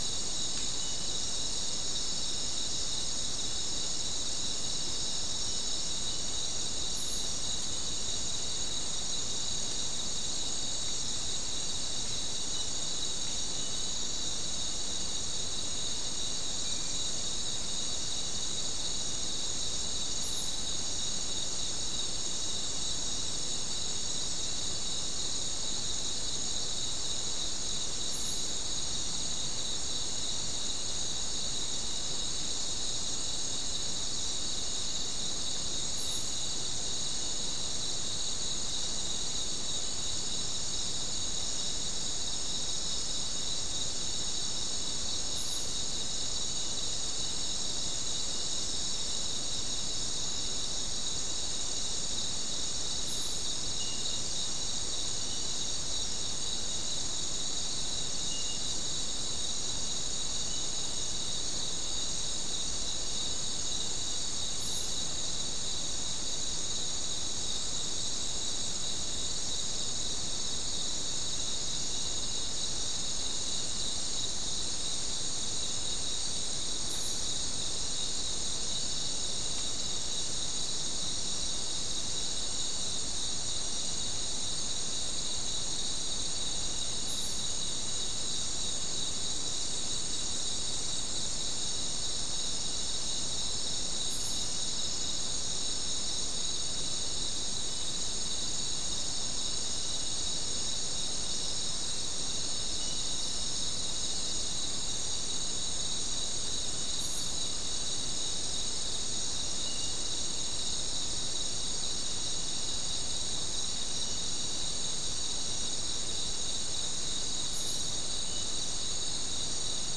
Non-specimen recording: Soundscape
Location: South America: Guyana: Sandstone: 2
Recorder: SM3